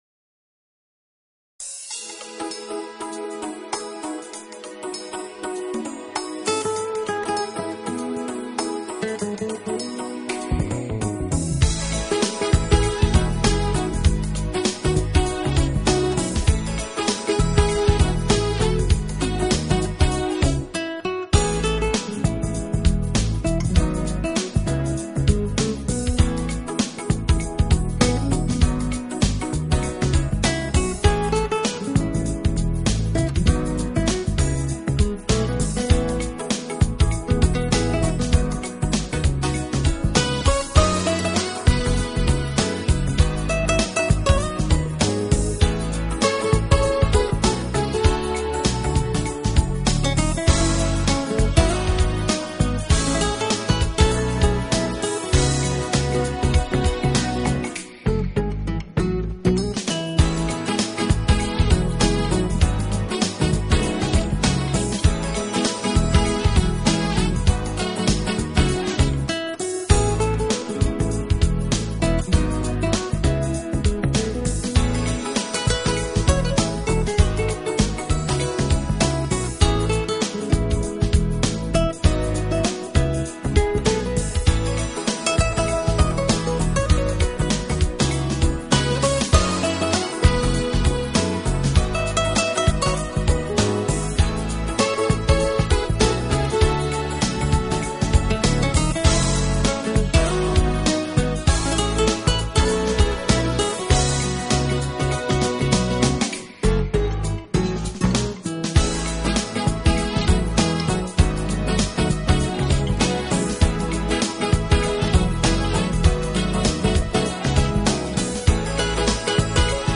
【爵士吉他】
音乐类型：Smooth Jazz
Guitar, Keyboards
Saxophone
Piano
Flute, Saxophone
Percussion, Drums
Bass